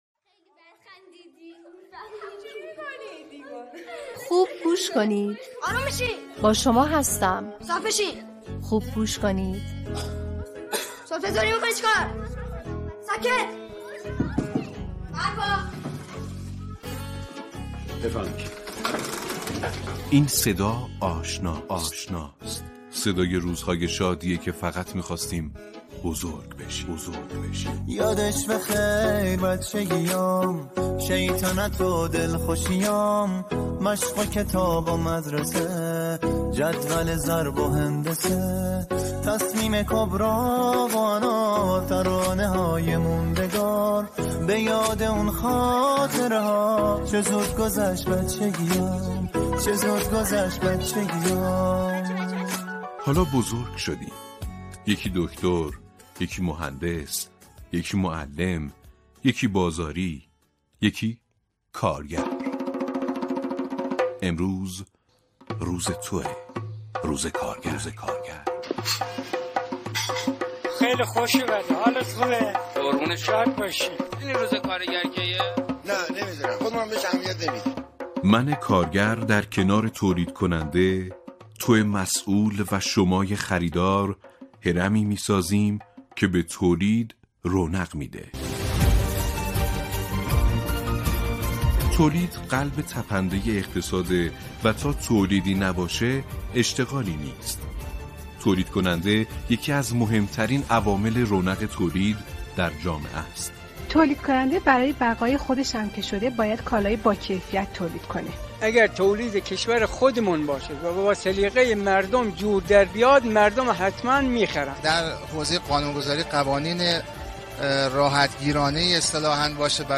دکلمه‌های زیبا درباره کارگر
دکلمه شماره یک